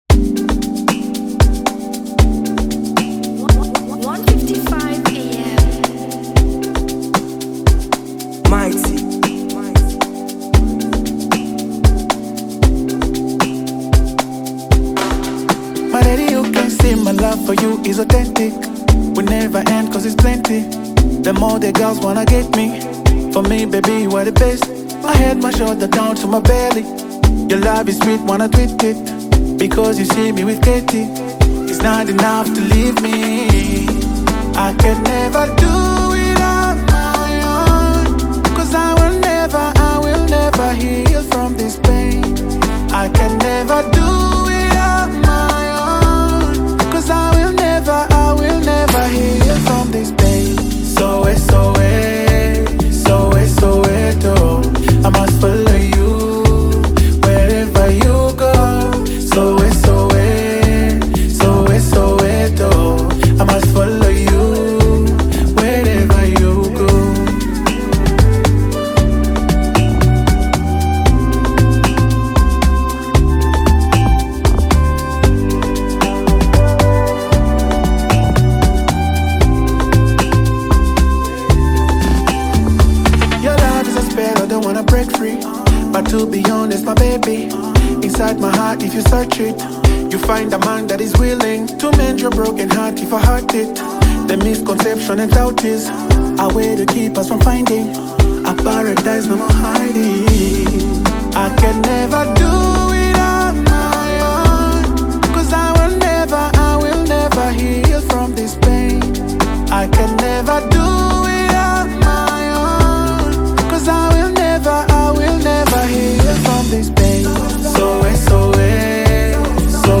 catchy Afrobeat track